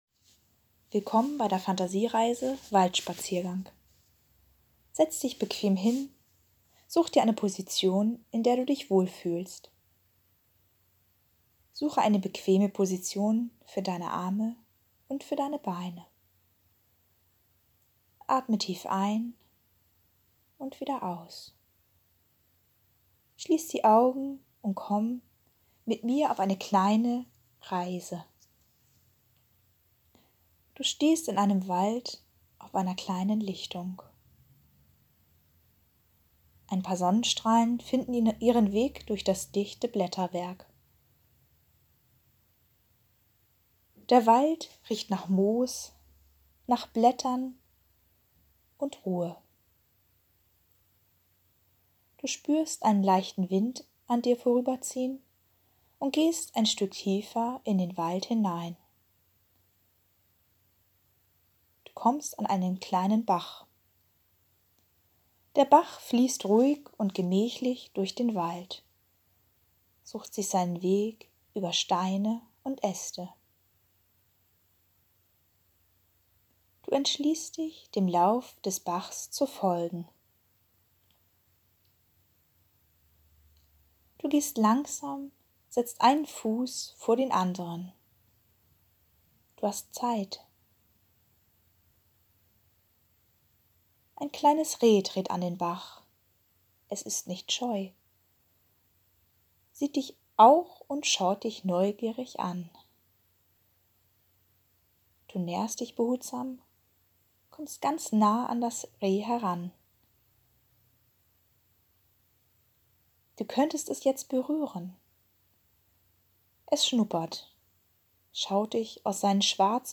Traumreisen
Fantasiereise Waldspaziergang ca.4 Minuten
F8-Fantasiereise-Waldspaziergang.mp3